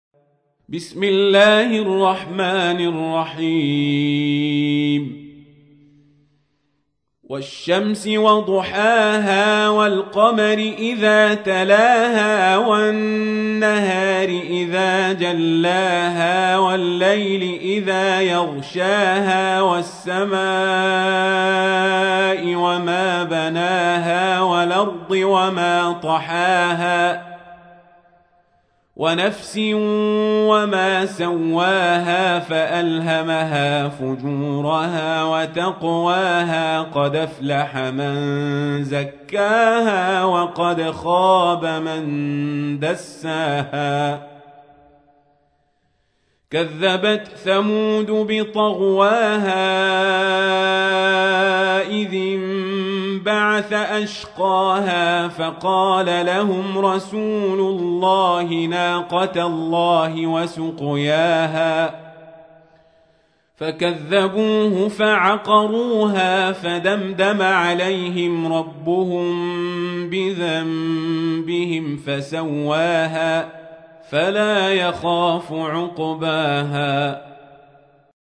تحميل : 91. سورة الشمس / القارئ القزابري / القرآن الكريم / موقع يا حسين